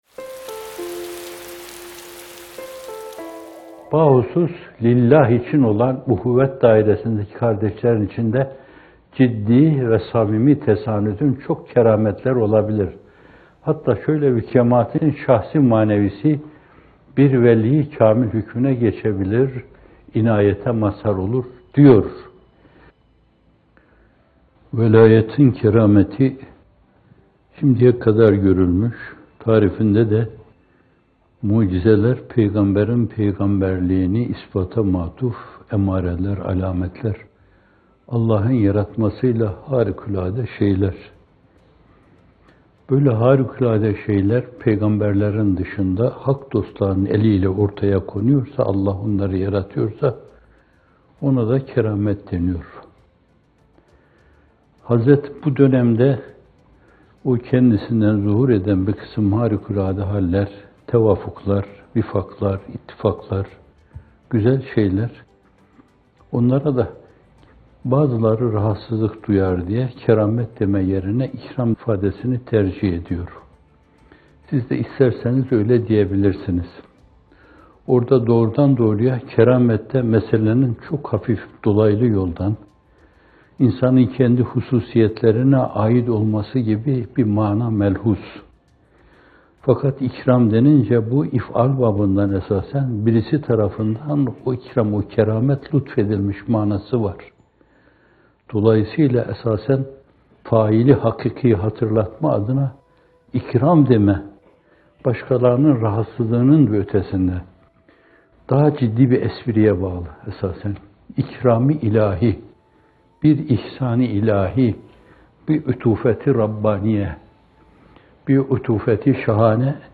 İkindi Yağmurları – İkram mı? Keramet mi? - Fethullah Gülen Hocaefendi'nin Sohbetleri
Not: Bu video, 23 Temmuz 2018 tarihinde yayımlanan “Kardeşliğin Kerâmeti” isimli Bamteli sohbetinden hazırlanmıştır.